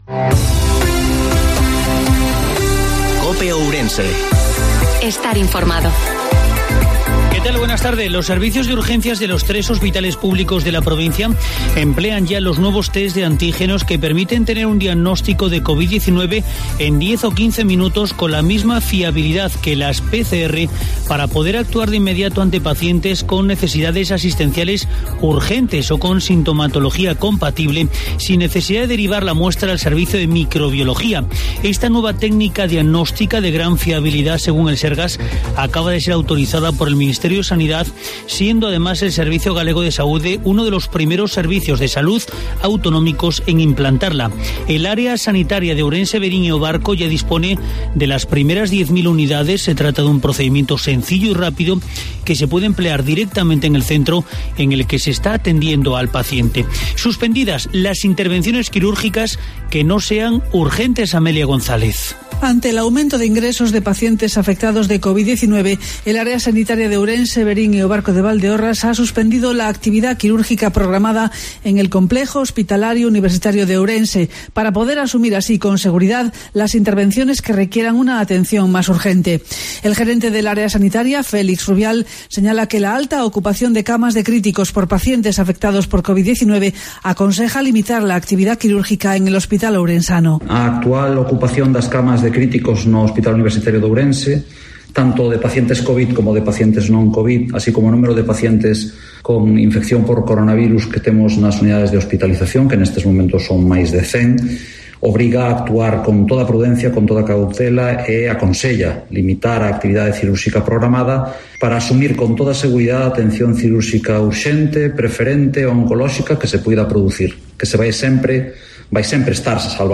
INFORMATIVO MEDIODIA COPÈ OURENSE